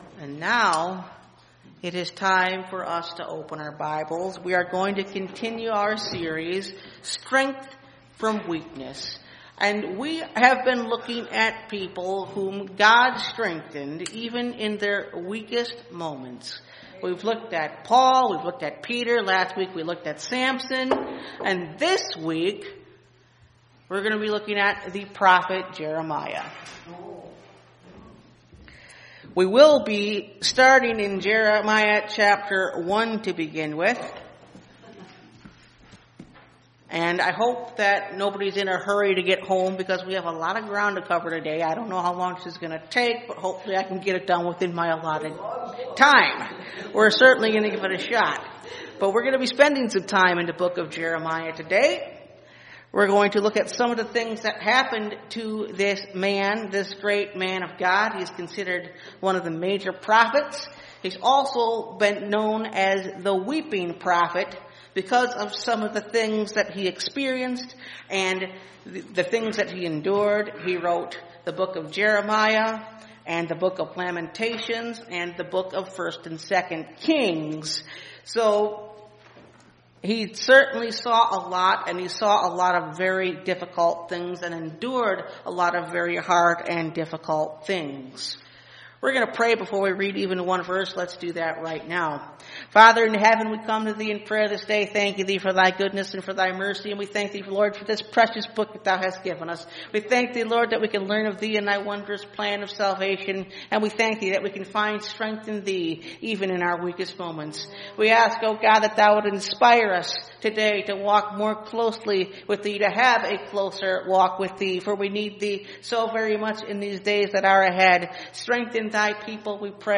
Strength From Weakness – Part 4 (Message Audio) – Last Trumpet Ministries – Truth Tabernacle – Sermon Library